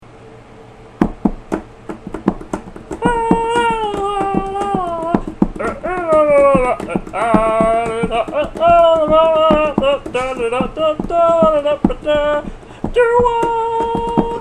drums
vocals